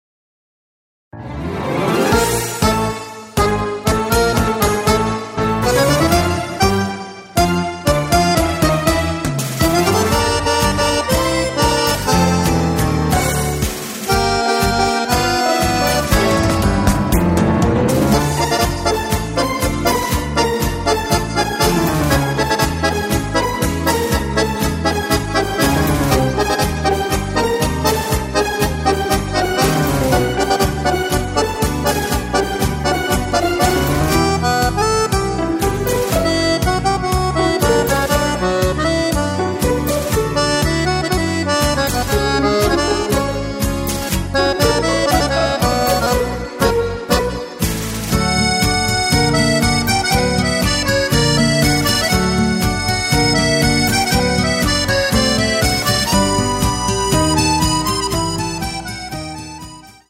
Playback - audio karaoke für Akkordeon